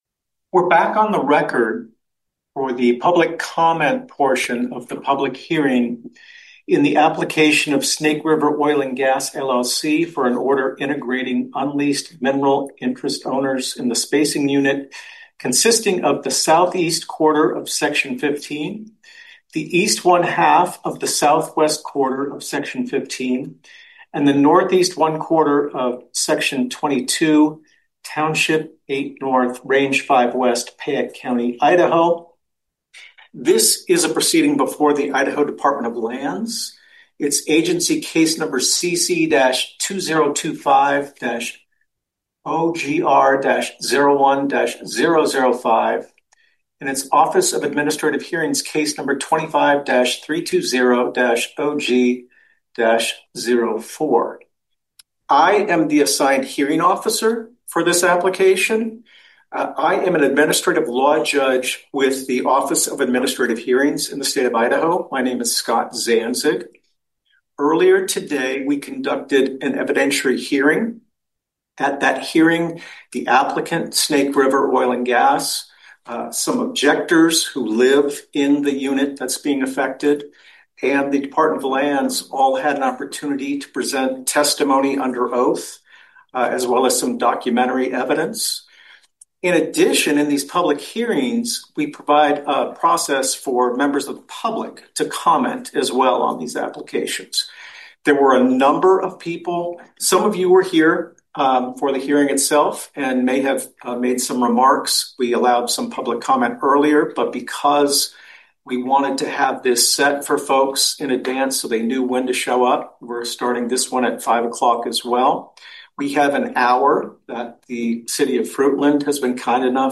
The Wednesday, December 24, 2025, Climate Justice Forum radio program, produced by regional, climate activists collective Wild Idaho Rising Tide (WIRT), features citizen testimony at a packed Idaho Department of Lands public hearing, opposing a Snake River Oil and Gas application to force hundreds of Fruitland property owners to lease and allow nearby dangerous drilling…